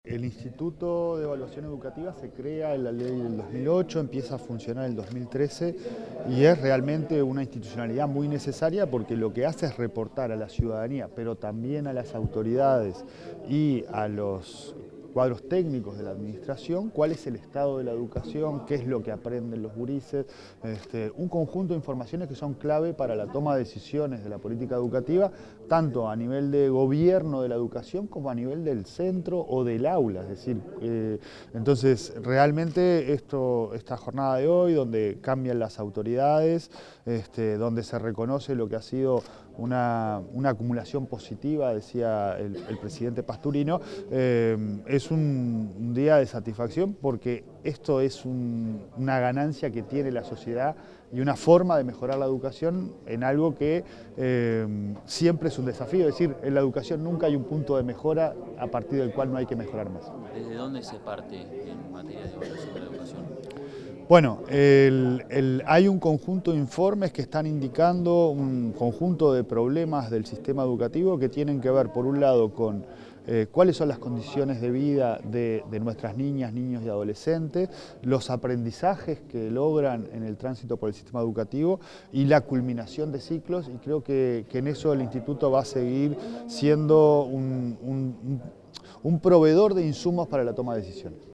Declaraciones del presidente de ANEP, Pablo Caggiani | Presidencia Uruguay
Declaraciones del presidente de ANEP, Pablo Caggiani 11/06/2025 Compartir Facebook X Copiar enlace WhatsApp LinkedIn El presidente de la Administración Nacional de Educación Pública (ANEP), Pablo Caggiani, dialogó con la prensa luego de la presentación de las autoridades del Instituto Nacional de Evaluación Educativa.